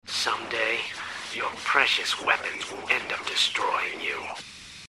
Sex: Male